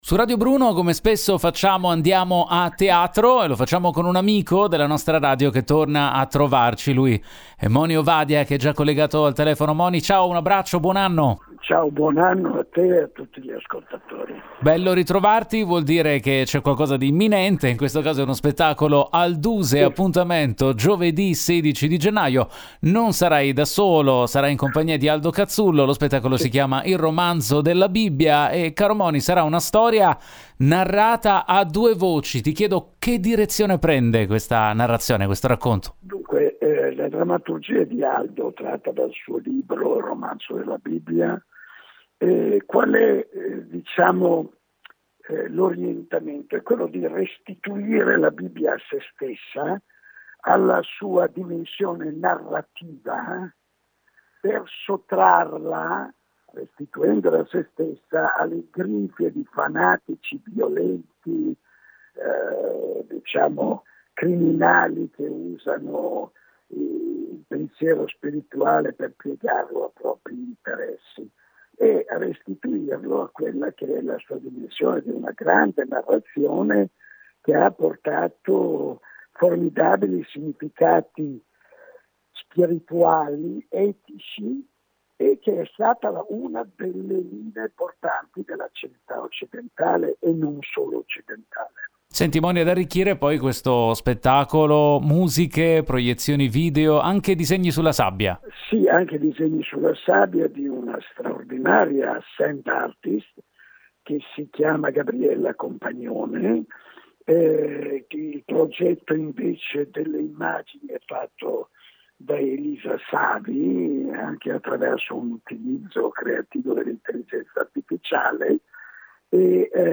Home Magazine Interviste Moni Ovadia presenta “Il romanzo della Bibbia”